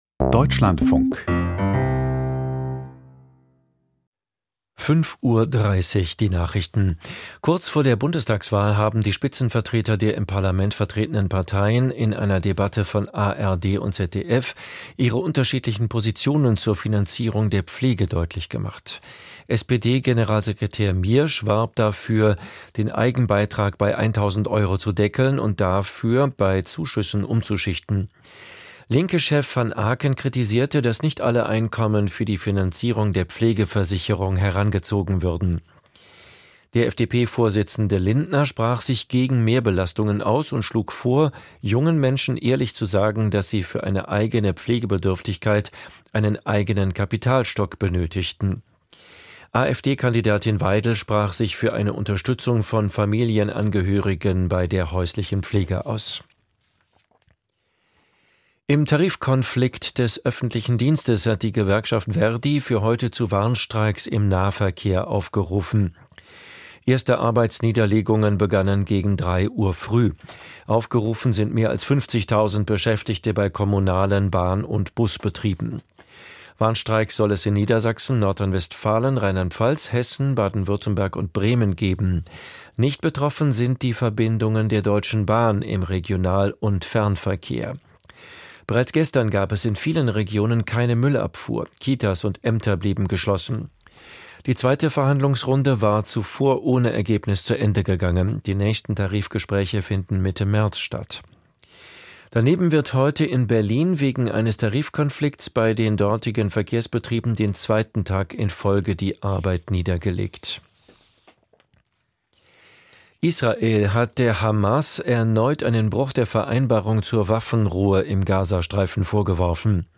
Die Nachrichten
Aus der Deutschlandfunk-Nachrichtenredaktion.